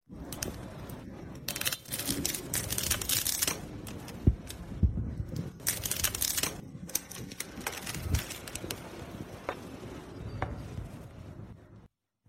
Tiếng bóc Siu, xé Seal, khui tem, mở hộp đồ mới… (Sample 2)
Thể loại: Tiếng động
Description: Là hiệu ứng âm thanh sống động ghi lại khoảnh khắc bóc tem sản phẩm, xé lớp seal bảo vệ, lột siu nilon, khui hộp hàng mới...
tieng-boc-siu-xe-seal-khui-tem-mo-hop-do-moi-sample-2-www_tiengdong_com.mp3